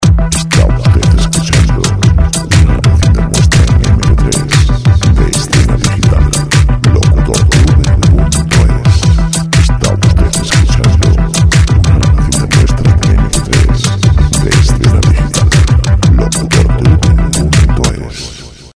Royalty free dance music